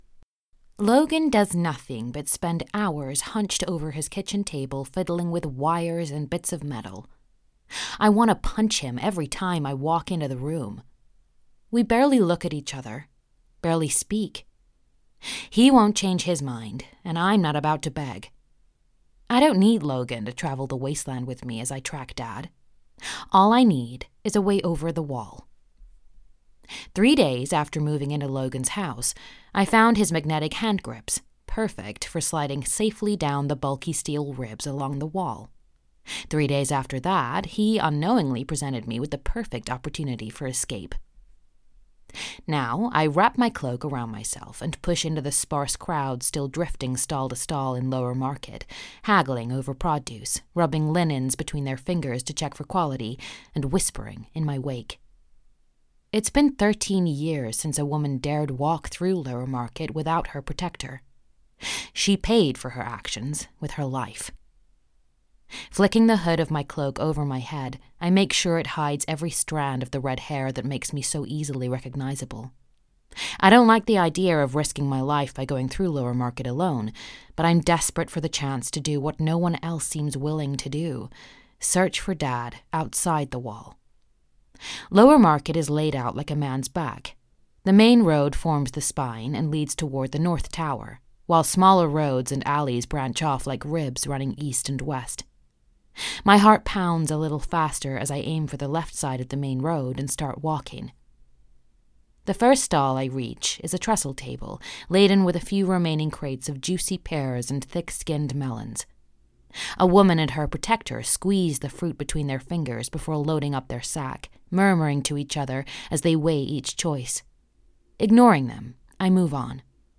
US Clip
• Home Studio